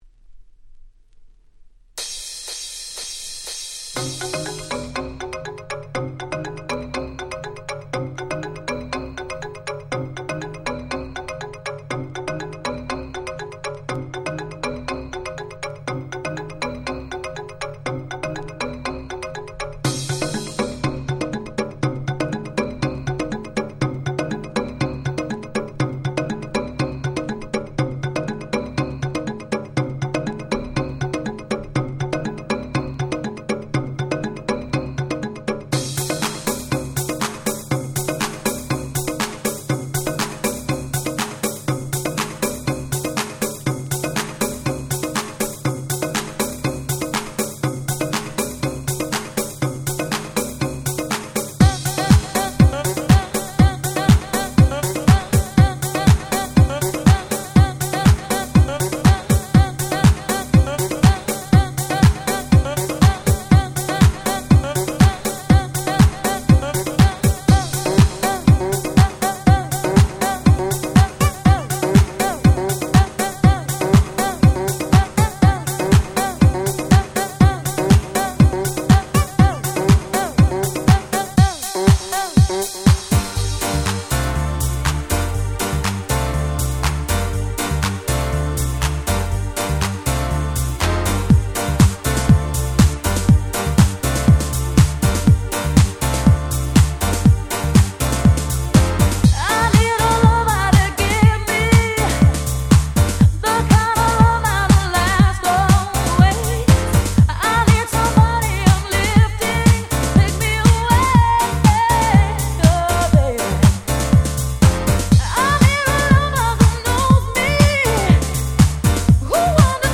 93' Nice Vocal House !!
数々のフロアを揺らして来たであろう最高のRemixです！！